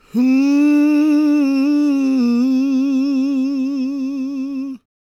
GOSPMALE329.wav